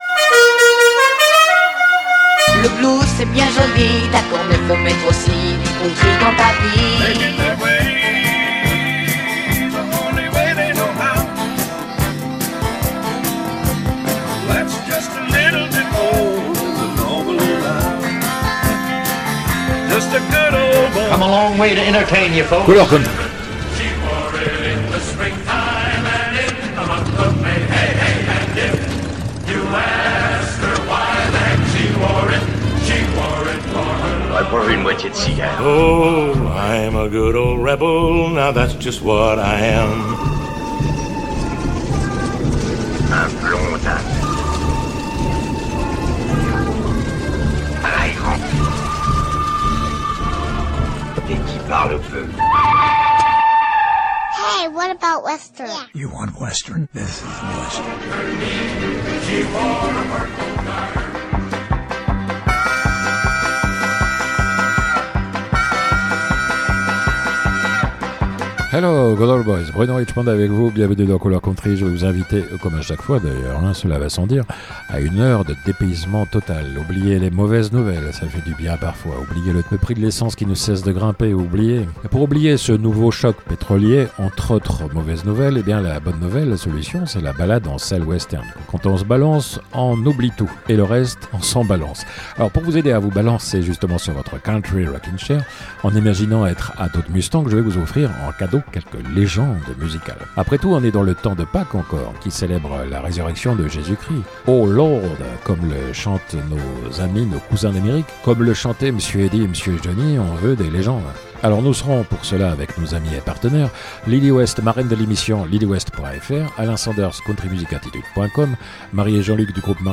Pour rien, sinon éventuellement un « merci », parmi les plus beaux succès vintage de la Country.